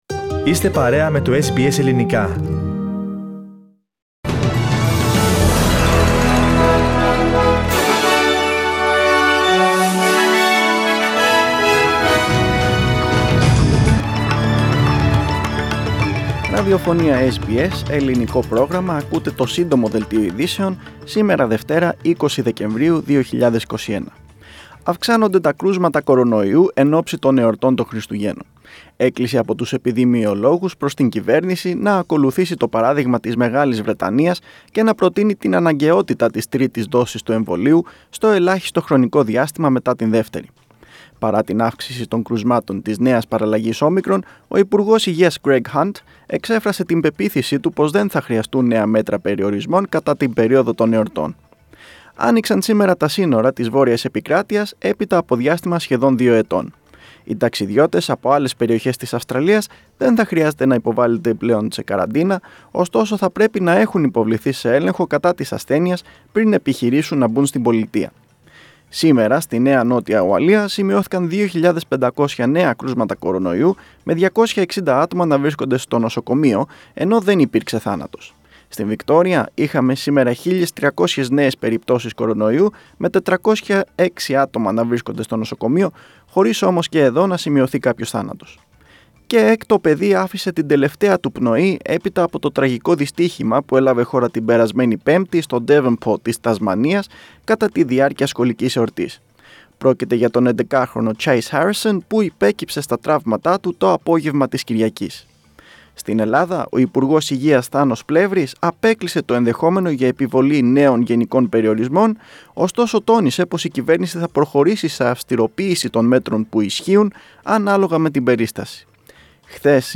News Flash - Σύντομο Δελτίο